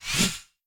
whooshForth_Farther.wav